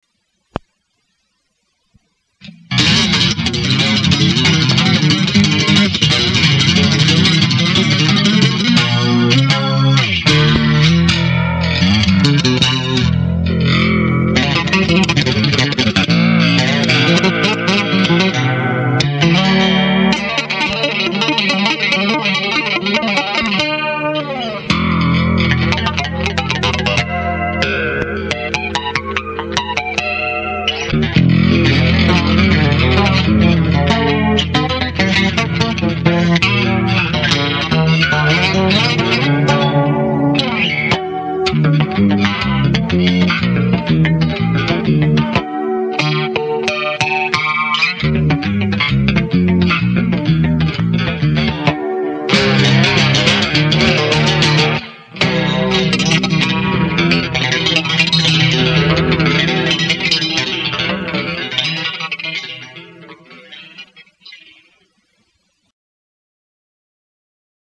NOTE: bass solo